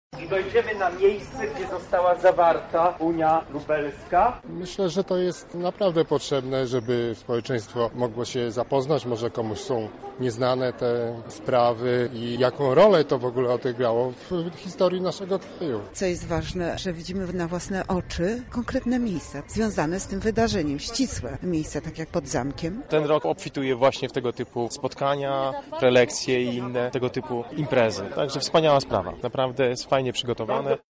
Zapytaliśmy uczestników o wrażenia: